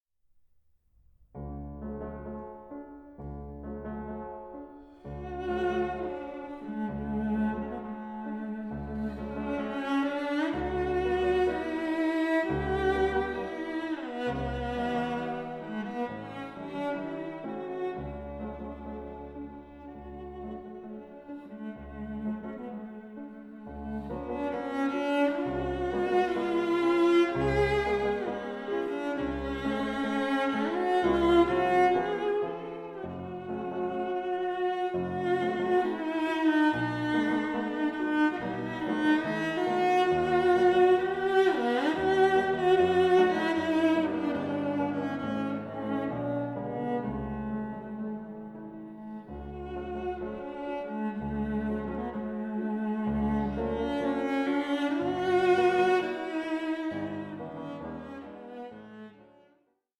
1837 Érard fortepiano